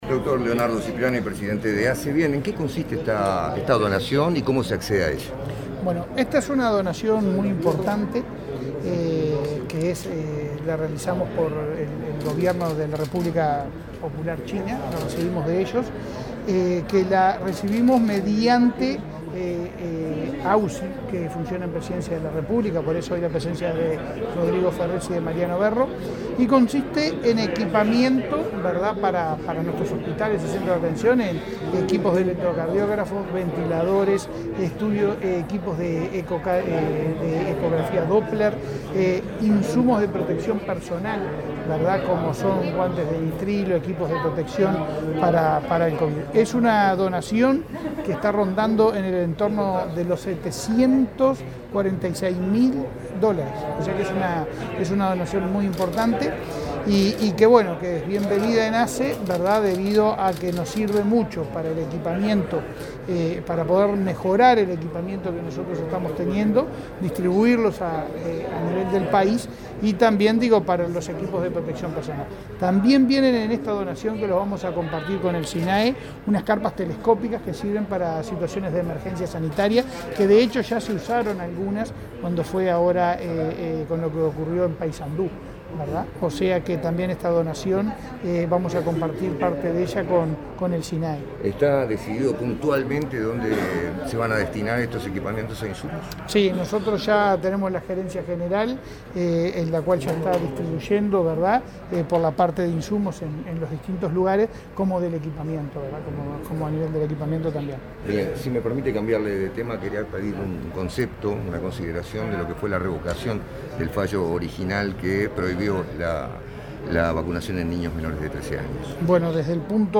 Declaraciones a la prensa del presidente de ASSE, Leonardo Cipriani